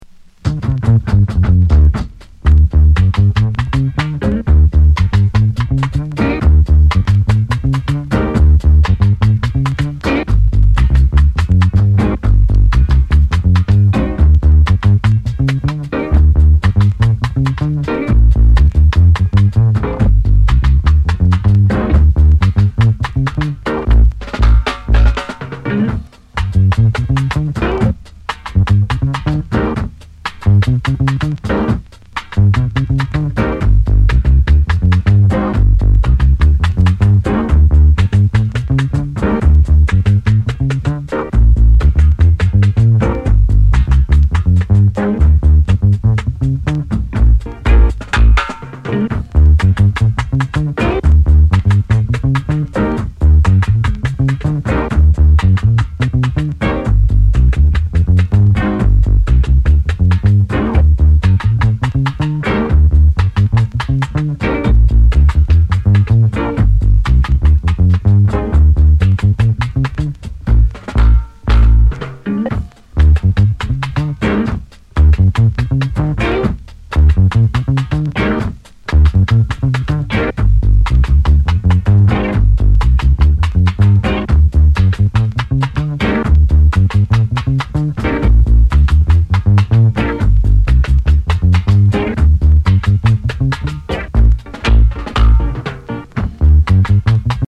銃声やハモンド効かせたスキンズ・チューンなどルーディー・チューン満載！